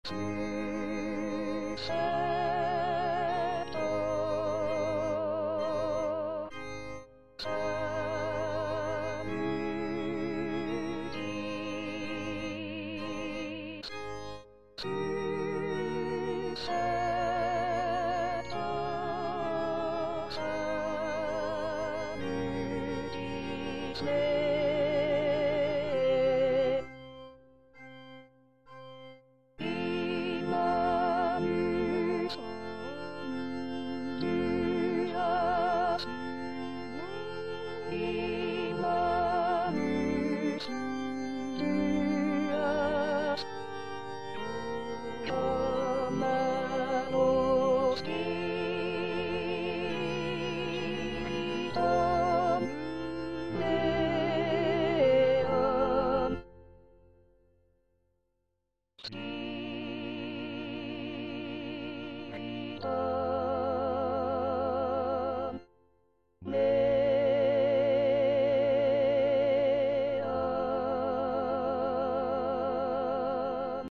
Parole 7: Pater, in manus tuas        Prononciation gallicane (à la française)